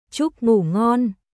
Chúc ngủ ngonチュック・グー・ゴンおやすみなさい（一般的な言い方）
ベトナム語の「ngủ（グー）」は鼻音で、日本語にない響きがあります。